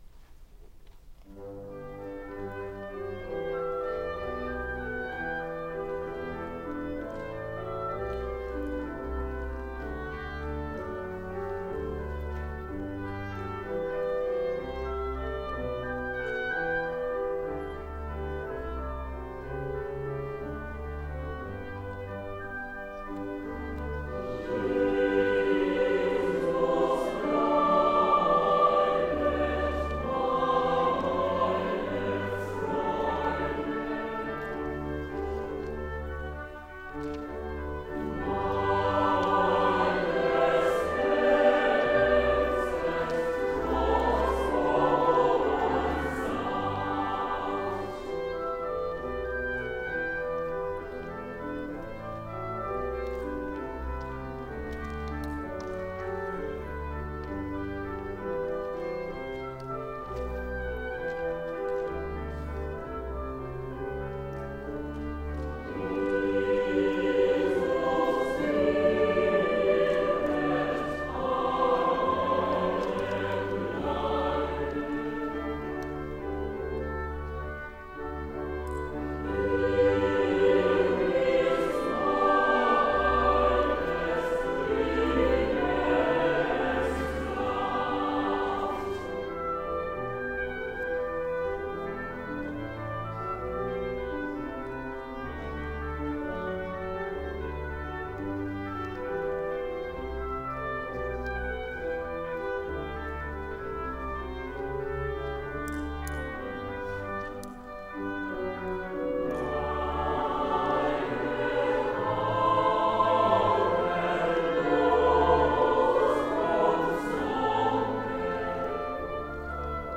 第３２回演奏会音源の公開棚
KYRIE 6.3 161 　　客席にセットした自前録音機の
アンコール曲#1として唱われた